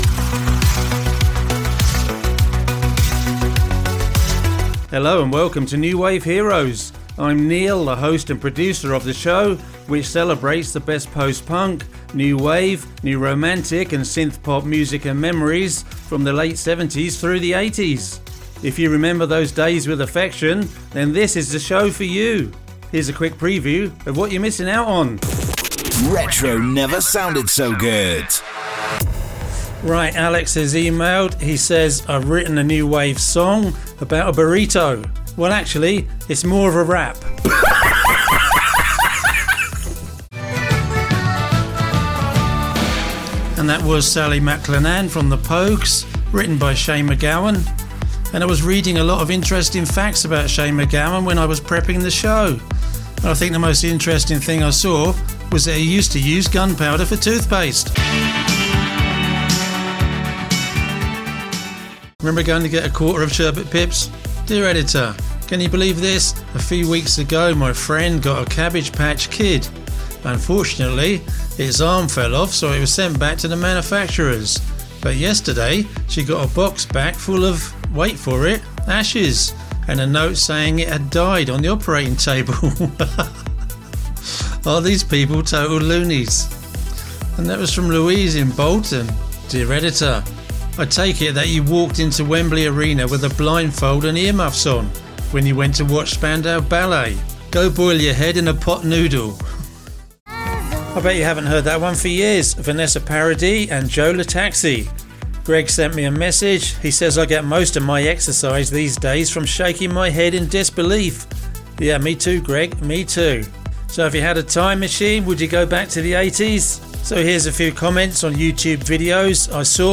Syndicated Retro
It's the show that plays the best, new wave, new romantic and synth pop music. All your favourite bands, artists, tracks and albums.